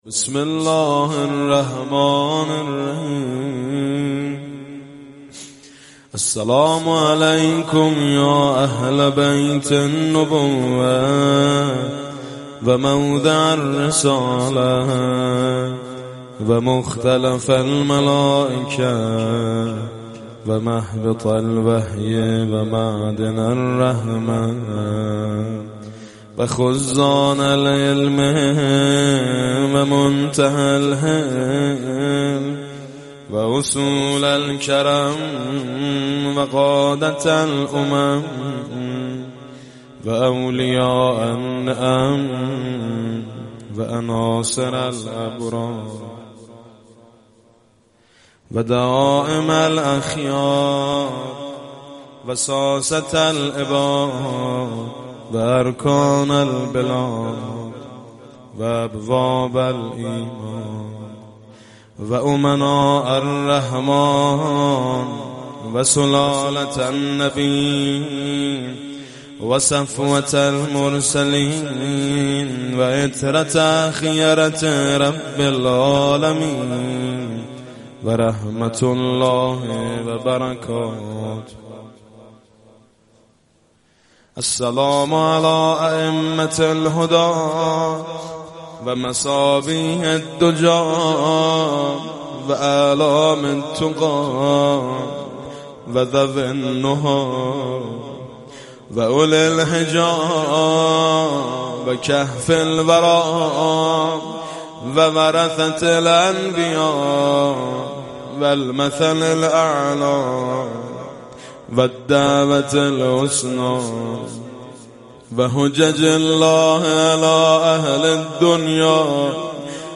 صوت/ زیارت جامعه کبیره بانوای میثم مطیعی
صوت زیارت جامعه کبیره بانوای میثم مطیعی به مناسبت روز شهادت صاحب این زیارت، امام هادی علیه السلام.